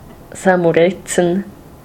St. Moritz (/ˌsæn məˈrɪts/ SAN mə-RITS, US also /ˌsnt -/ SAYNT -, UK also /sənt ˈmɒrɪts/ sənt MORR-its; German, in full: Sankt Moritz [zaŋkt moˈrɪts, ˈmoːrɪts] locally [saŋkt]; Romansh: San Murezzan [sam muˈʁetsən]
Roh-putèr-San_Murezzan.ogg.mp3